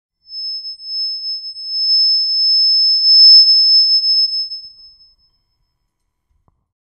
Звуки радиосигналов
Высокий частотный писк